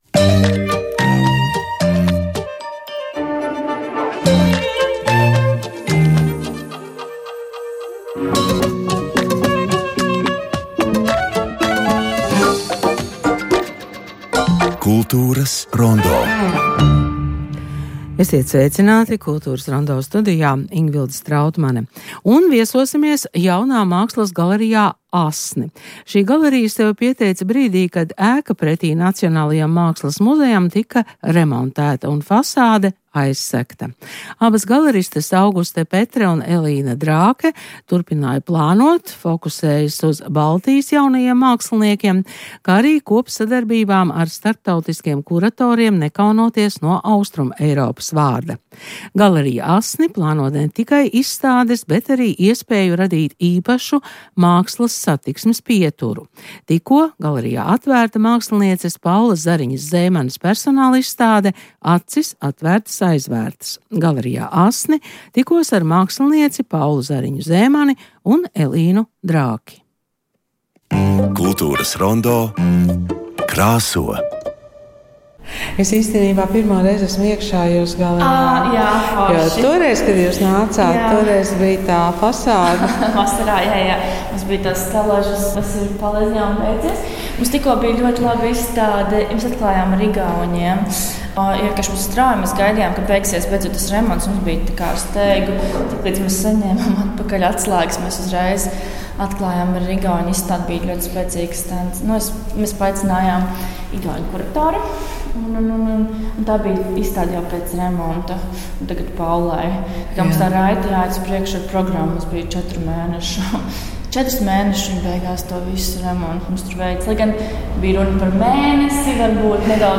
Saruna par jaunās galerijas “ASNI” pieredzi